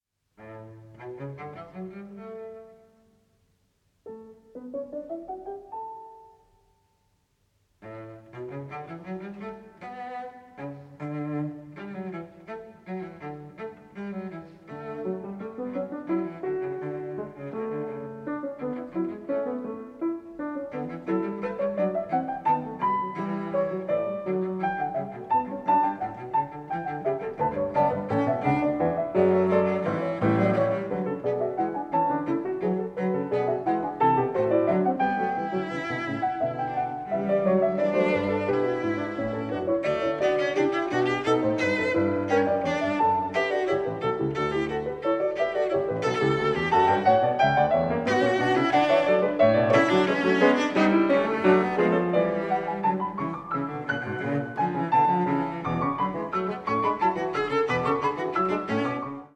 Sonata for Cello and Piano No 5 in D, Op 102 No 2